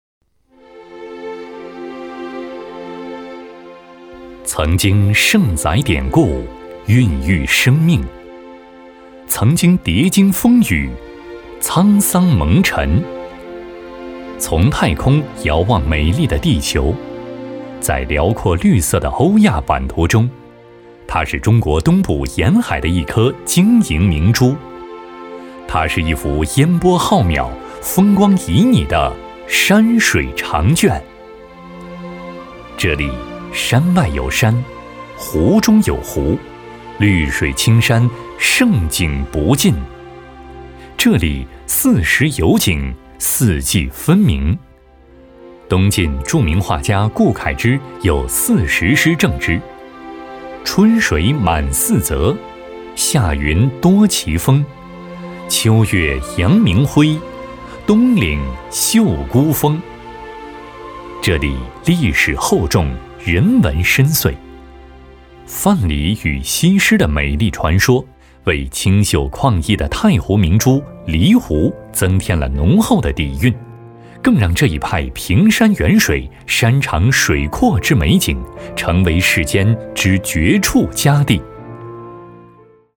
男57温情旁白-纵声配音网
男57 无锡（大气厚重）.mp3